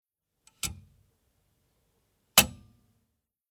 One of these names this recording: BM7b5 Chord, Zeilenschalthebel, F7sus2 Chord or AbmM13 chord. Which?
Zeilenschalthebel